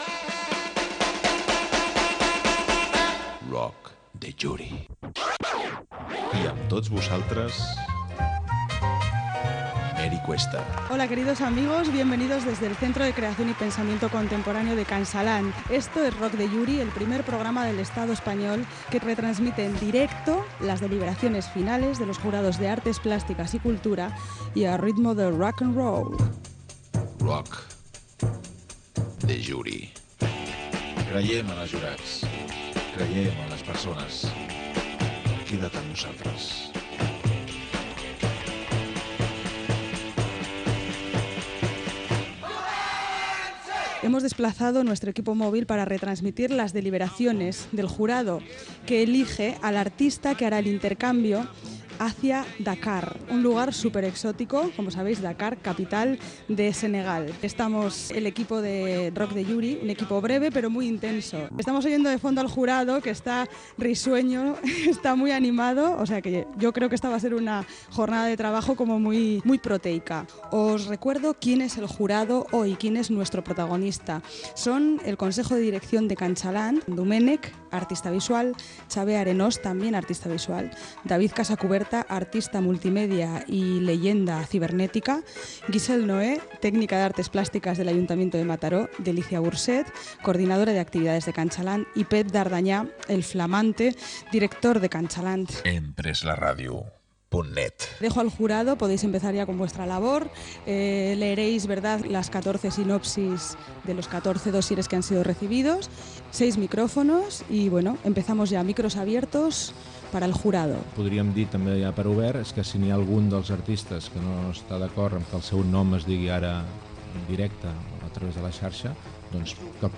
Careta del programa, inici de l'espai fet des de Can Xalant, Centre de Creació i Pensament Contemporani de Mataró, per atorgar el premi d'una estada a Dakar (Senegal) a un artista.